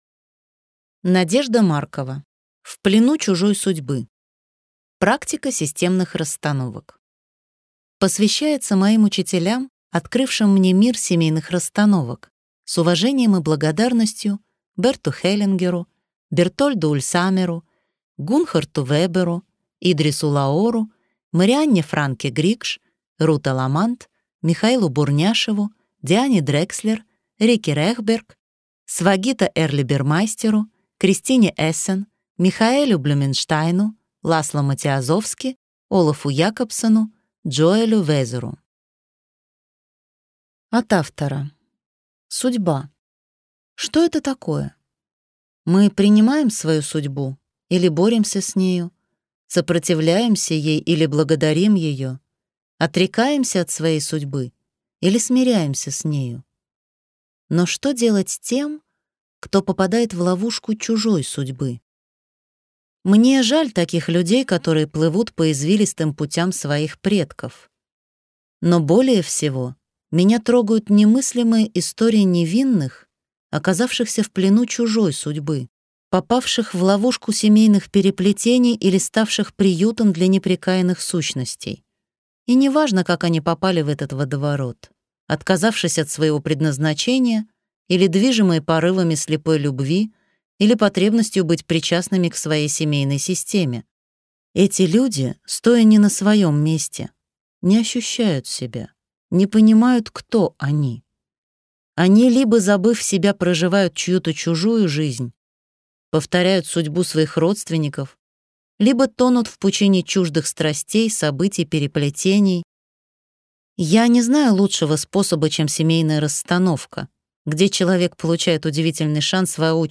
Аудиокнига В плену чужой судьбы. Практика системных расстановок | Библиотека аудиокниг